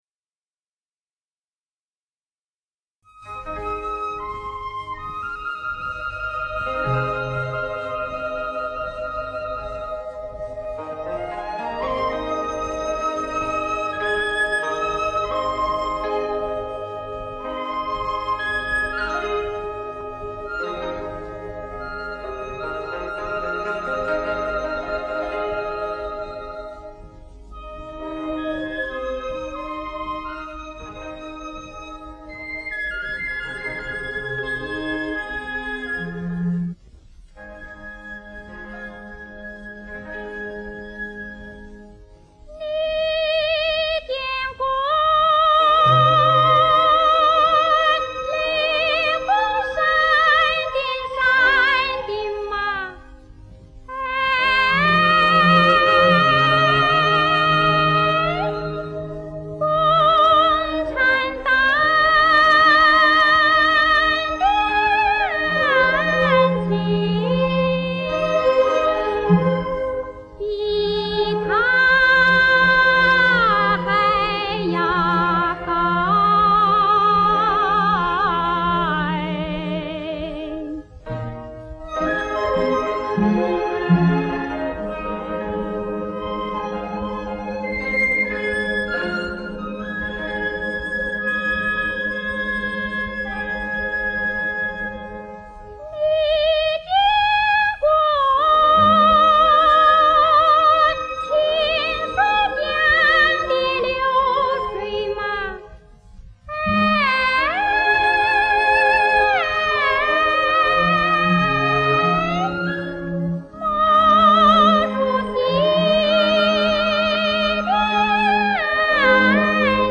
苗族飞歌是苗族民间歌曲的一种,曲调高亢奔放。